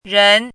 chinese-voice - 汉字语音库
ren2.mp3